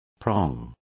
Also find out how gajo is pronounced correctly.